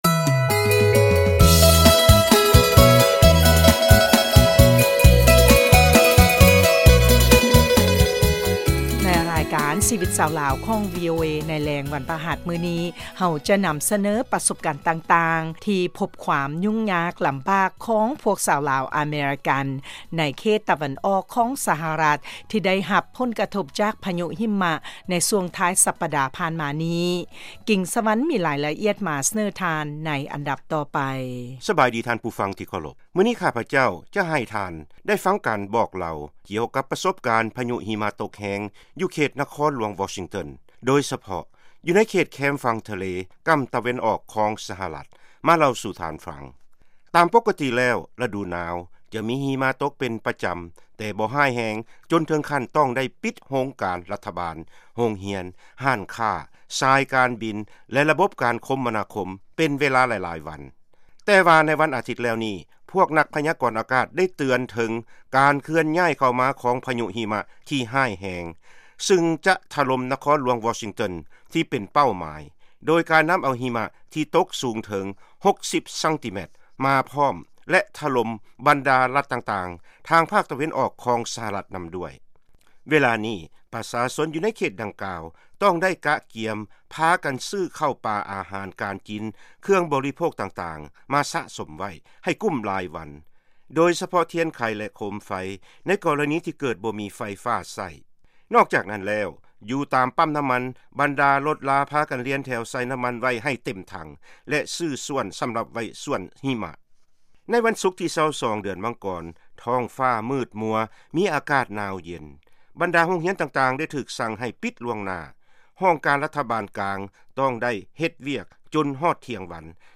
ເຊີນຟັງການສຳພາດ ຊາວລາວ-ອາເມຣິກັນ ກ່ຽວກັບປະສົບການ ພະຍຸຫິມະ